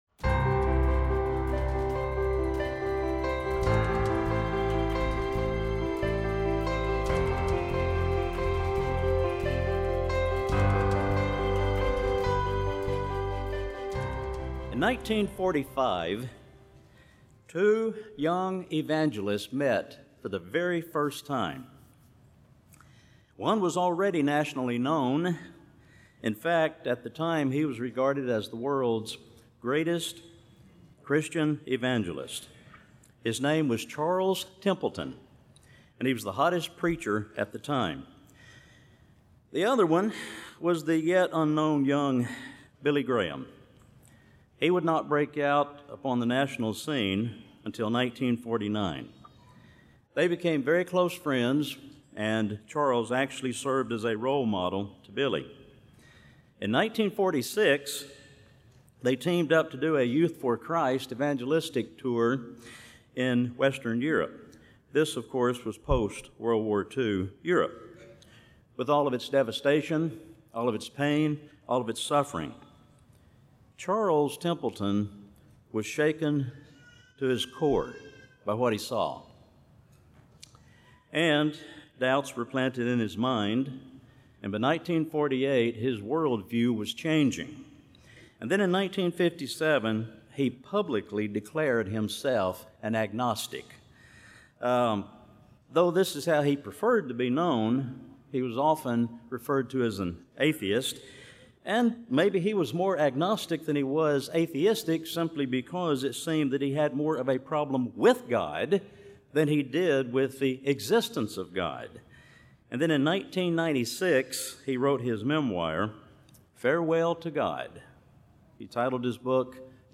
This sermon was given at the Panama City Beach, Florida 2019 Feast site.